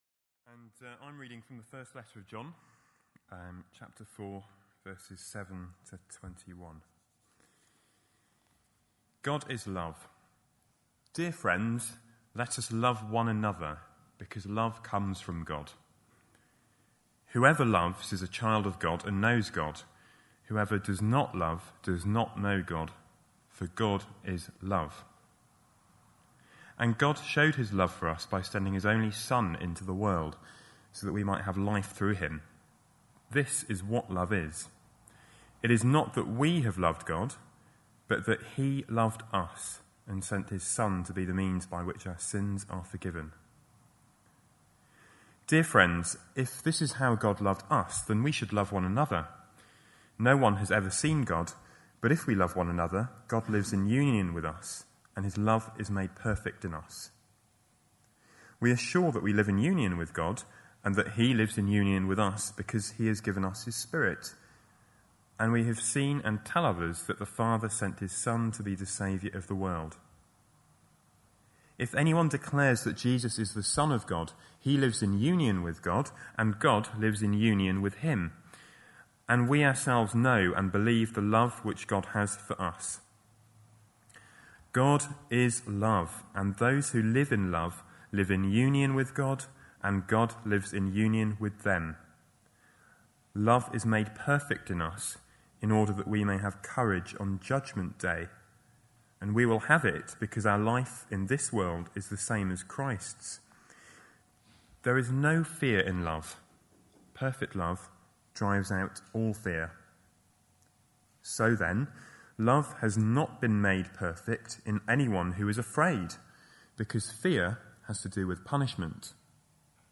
A sermon preached on 18th April, 2010, as part of our Love Actually series.